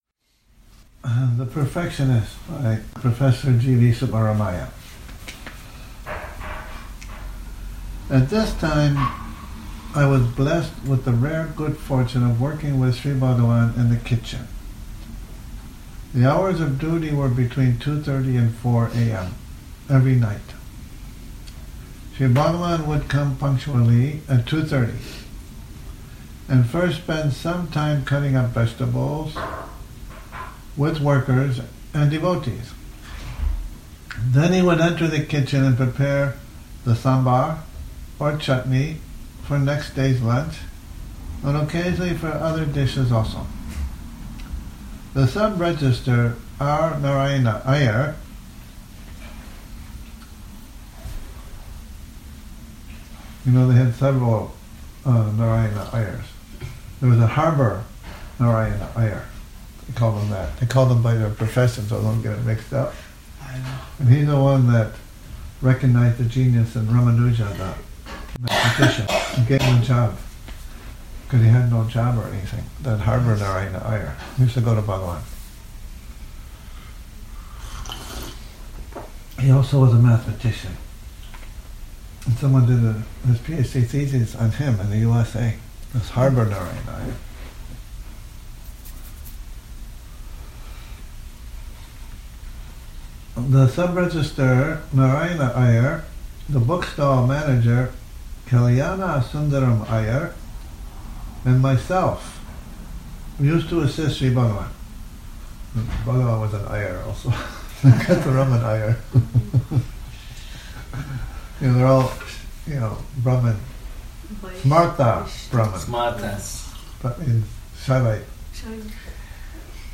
Morning Reading, 02 Nov 2019